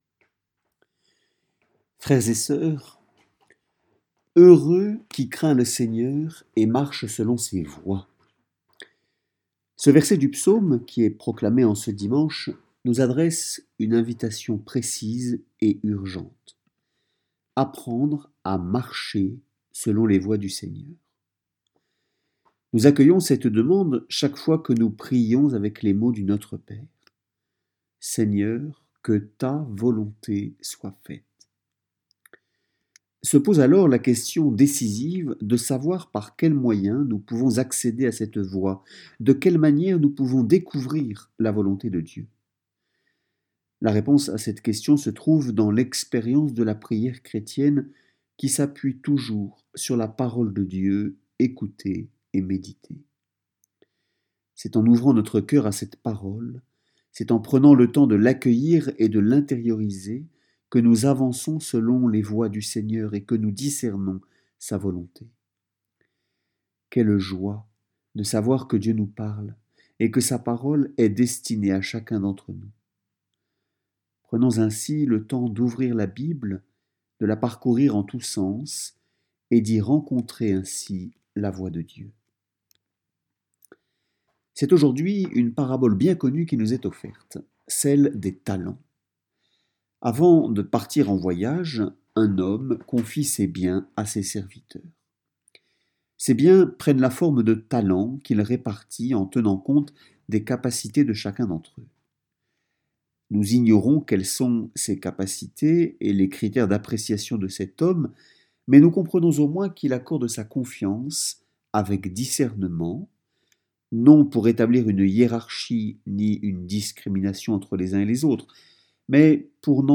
Homélie-dimanche-15-novembre.mp3